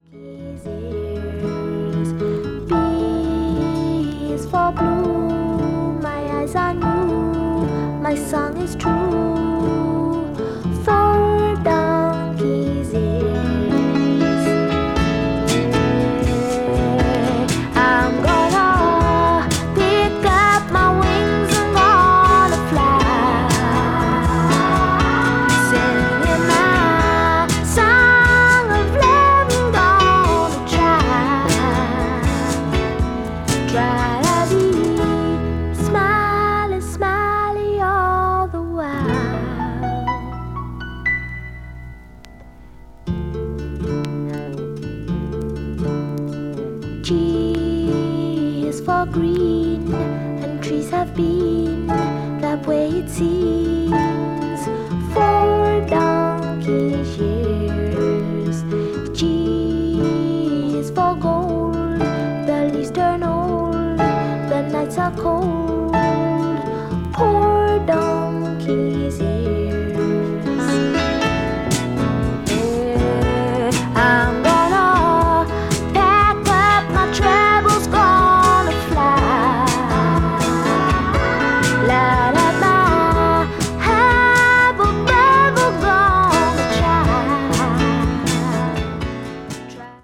folk   mellow groove   r&b   soul